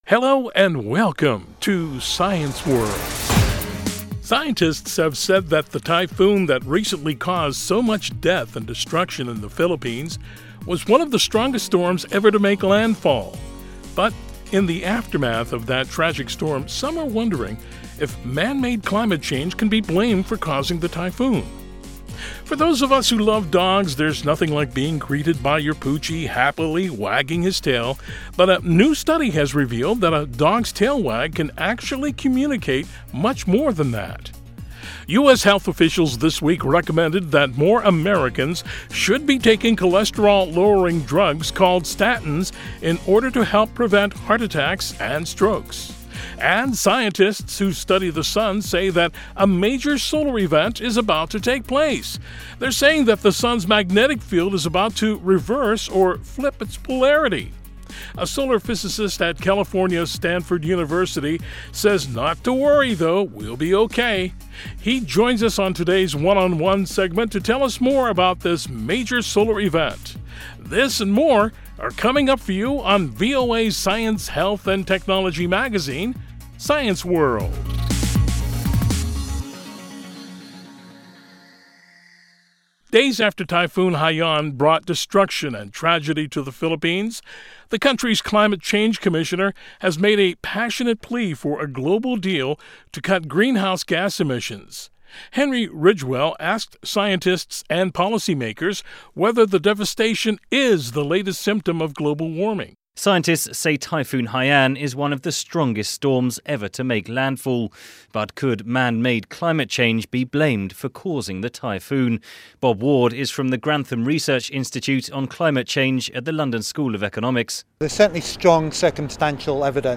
He joins us on today's One on One Segment to tell us more about this solar event.